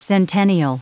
Prononciation du mot centennial en anglais (fichier audio)
Prononciation du mot : centennial